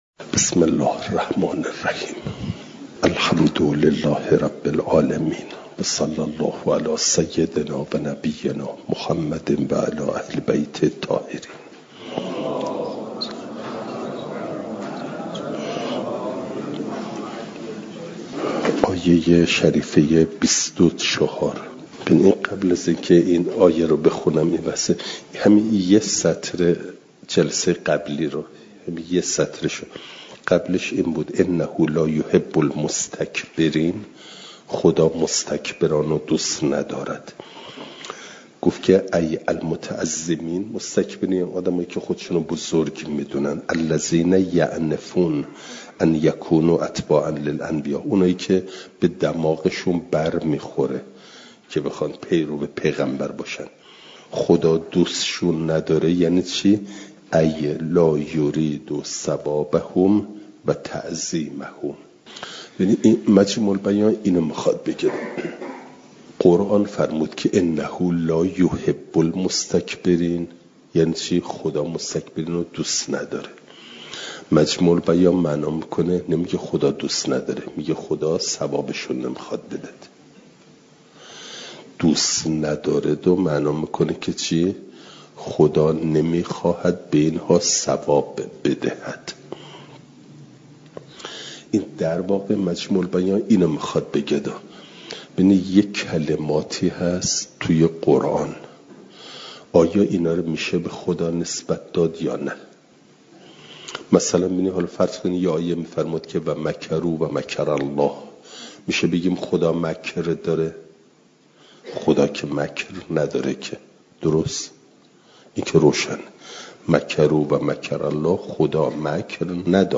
مجمع البیان | جلسه ۸۵۲ – دروس استاد